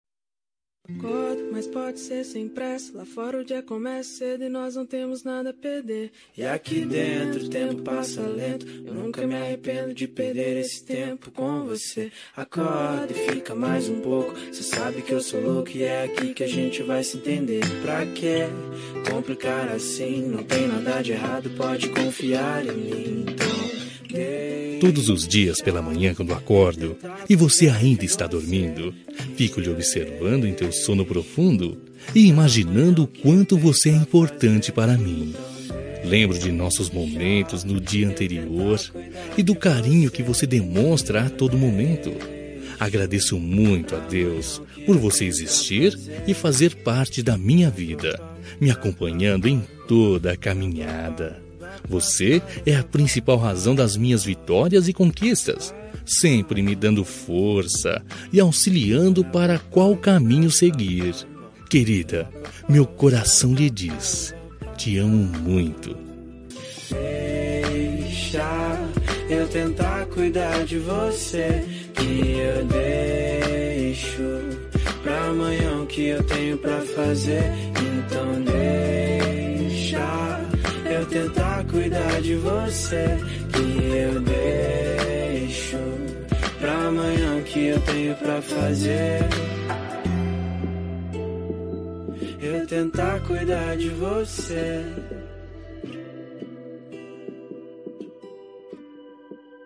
Telemensagem Romântica -Voz Masculino – Cód: 4104 Linda
4104-agradecimento-masc-romantica.m4a